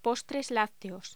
Locución: Postres lácteos